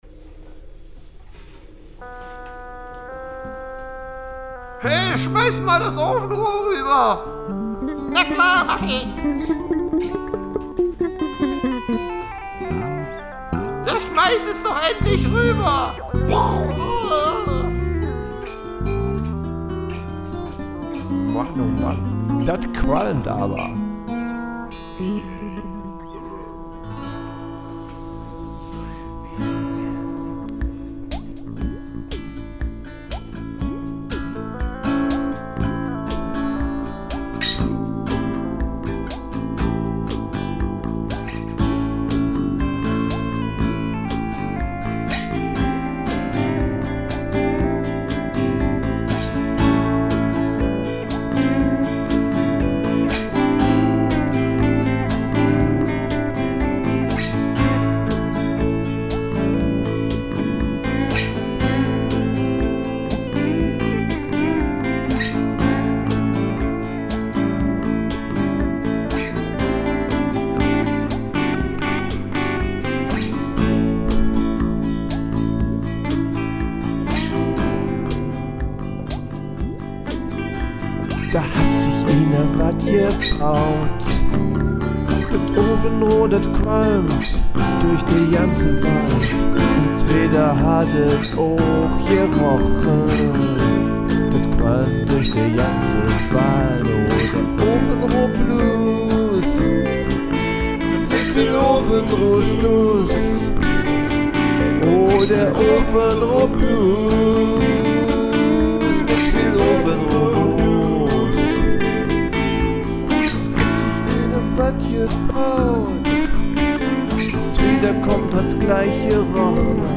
Handmade Musik keine KI.
Country & Western Playlist